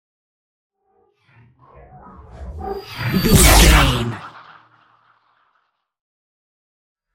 Sci fi shot whoosh to hit
Sound Effects
futuristic
intense
whoosh